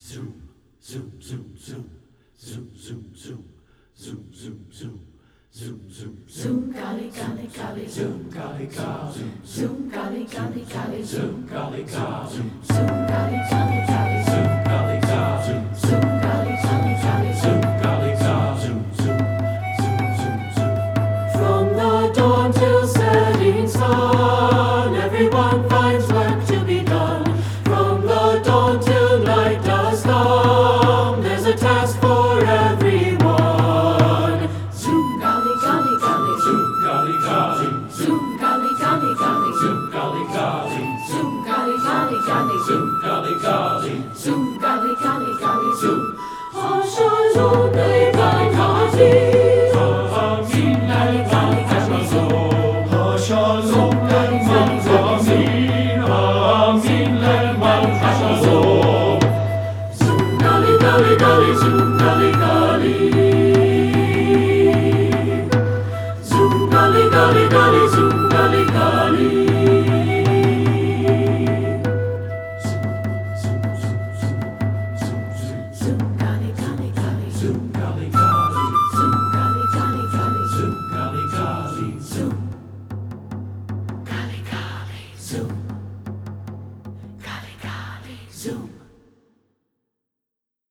Composer: Israeli Folk Song
Voicing: SATB, Hand Drum and Flute